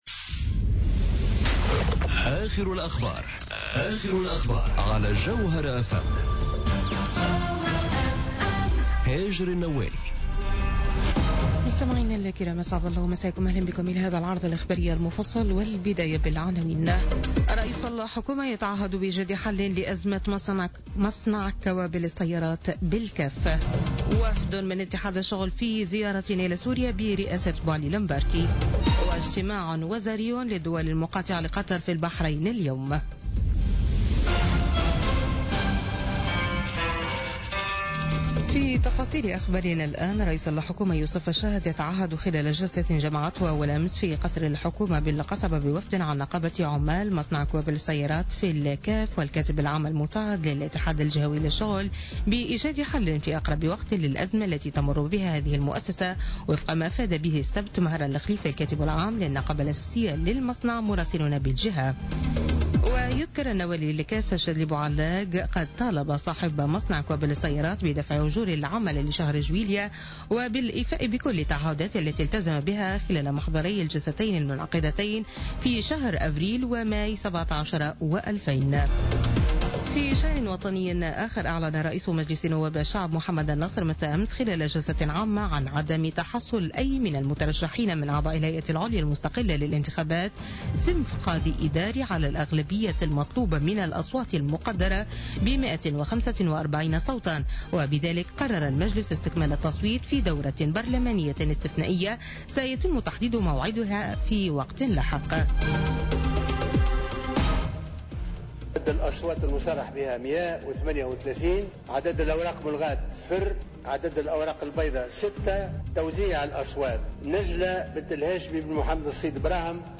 نشرة أخبار منتصف الليل ليوم الأحد 30 جويلية 2017